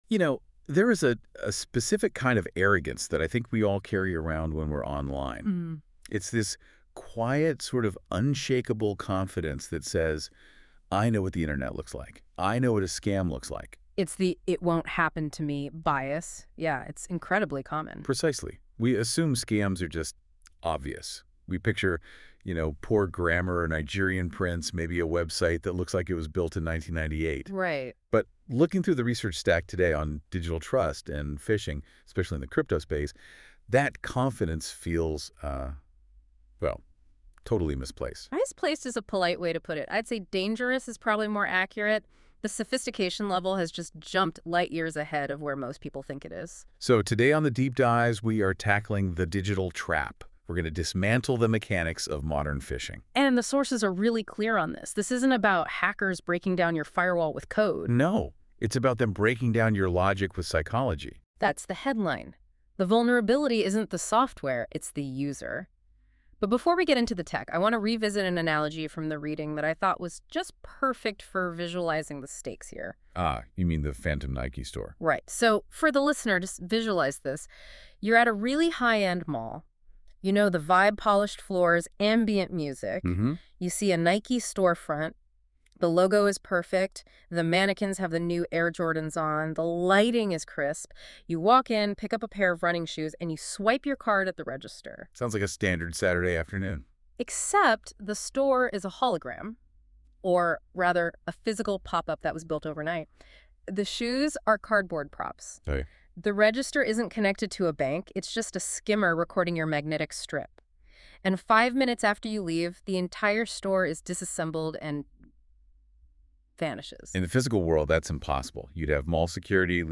hashtag🎧 Lesson Podcast